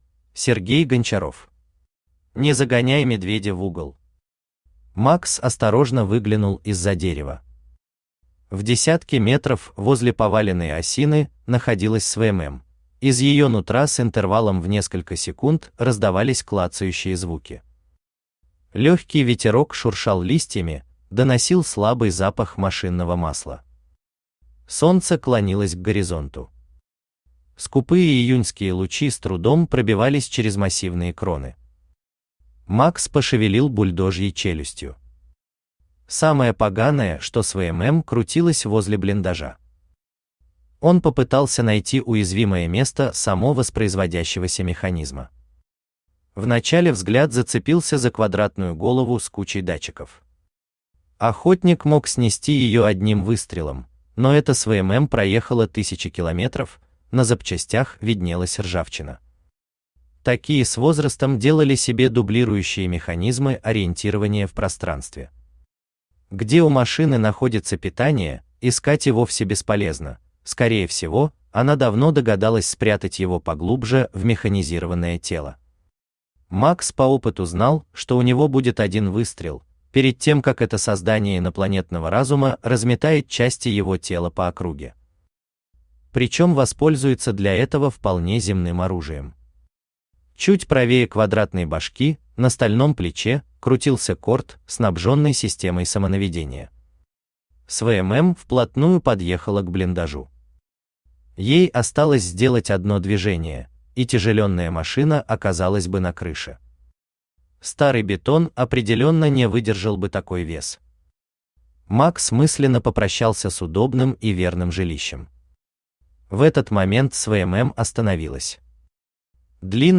Аудиокнига Не загоняй медведя в угол | Библиотека аудиокниг
Aудиокнига Не загоняй медведя в угол Автор Сергей Гончаров Читает аудиокнигу Авточтец ЛитРес.